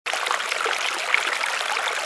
agua
agua.mp3